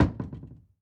Cupboard Door / close